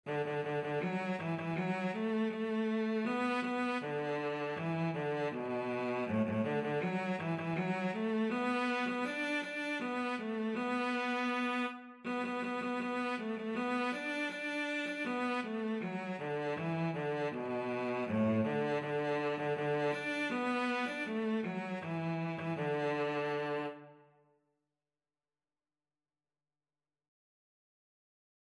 2/2 (View more 2/2 Music)
A3-D5
D major (Sounding Pitch) (View more D major Music for Cello )
Cello  (View more Easy Cello Music)
Traditional (View more Traditional Cello Music)